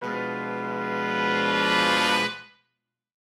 Index of /musicradar/gangster-sting-samples/Chord Hits/Horn Swells
GS_HornSwell-Fdim.wav